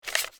paper_pickup2.wav